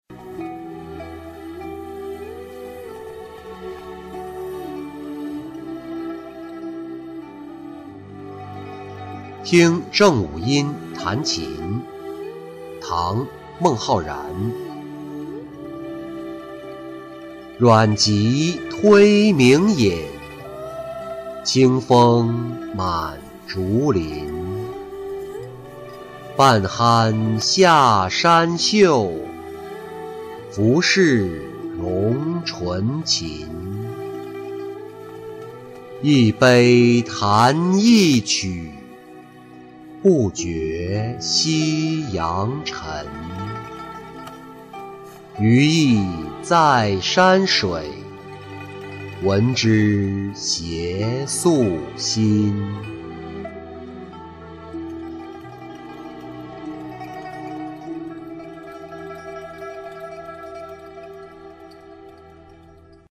听郑五愔弹琴-音频朗读